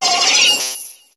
Cri de Mimitoss dans Pokémon HOME.